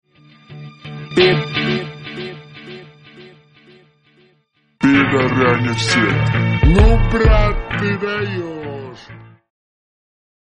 гитара
мужской голос
Mashup